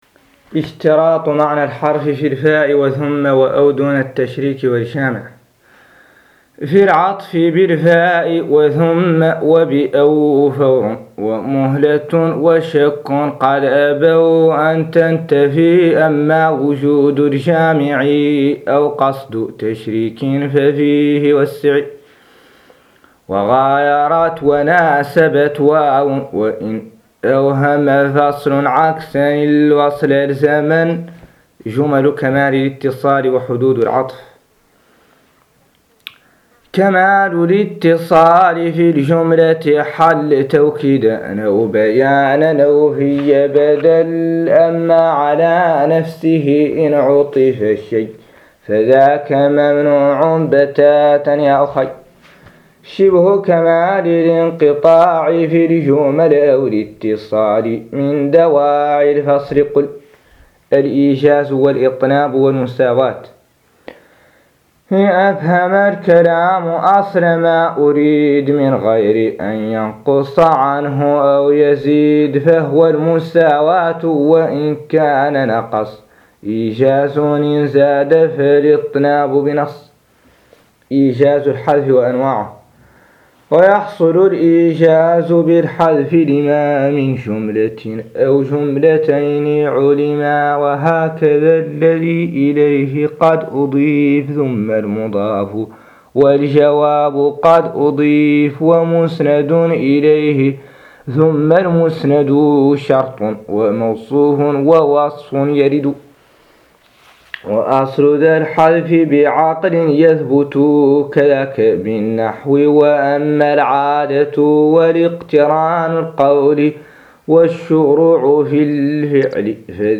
قراءة نظم فاتح البلاغة - الجزء الثاني